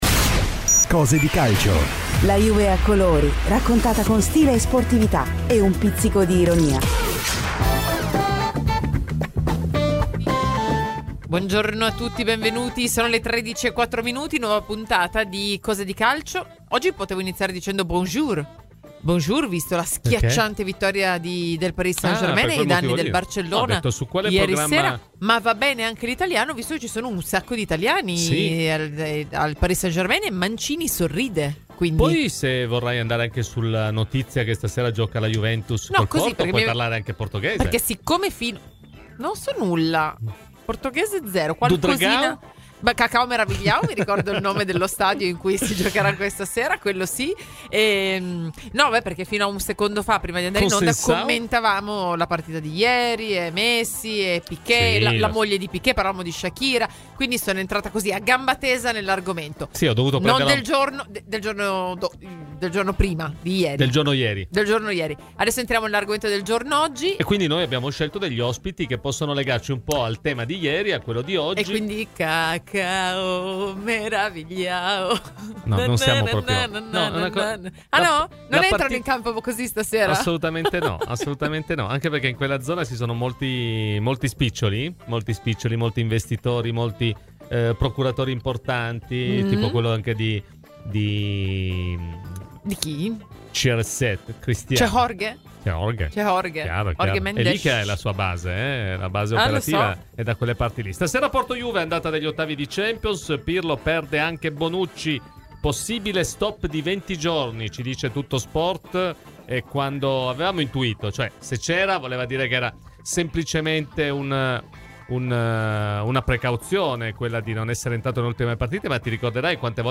Ai microfoni di Radio Bianconera